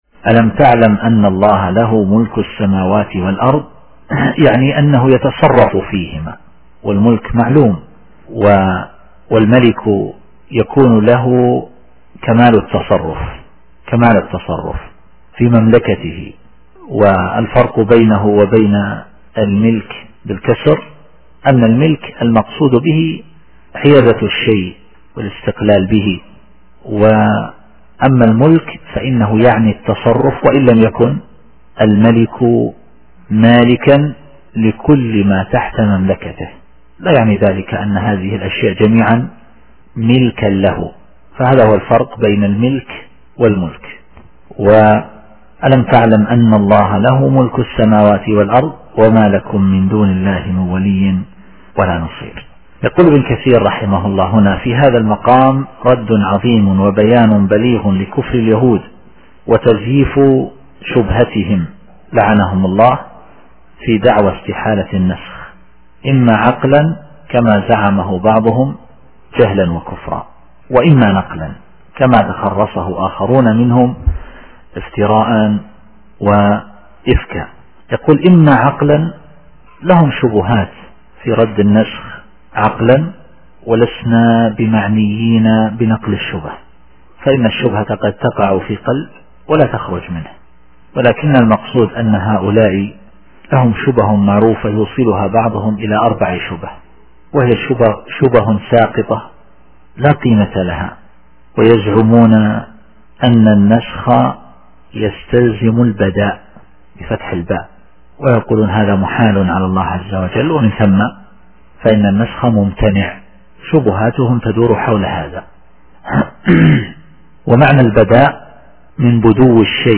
التفسير الصوتي [البقرة / 107]